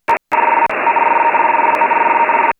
Маркер на 10107 кгц